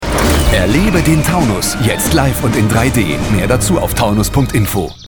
Aktionsspot und Reminder fassen in aller Kürze das Wichtigste zusammen und bleiben dank ihrer Wiederholungen gut in Erinnerung: